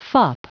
Prononciation du mot fop en anglais (fichier audio)
Prononciation du mot : fop